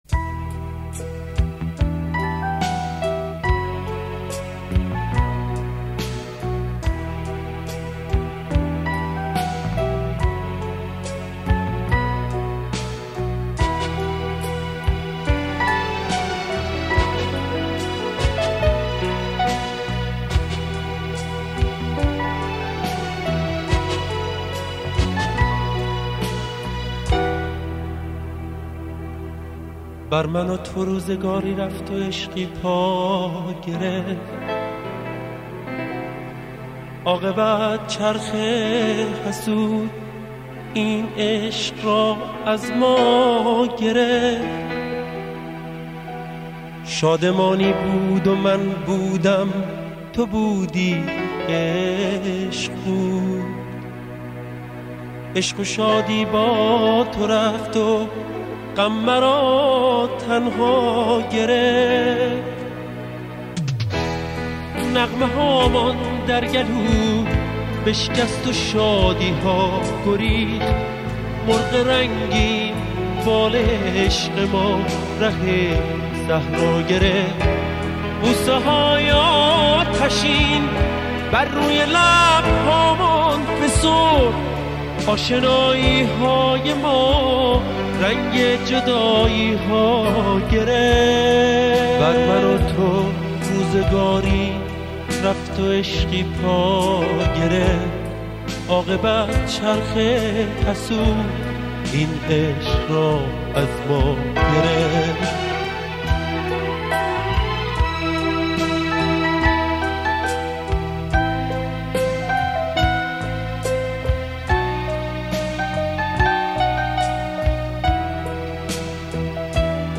• سنتی ایرانی
دسته : سنتی ایرانی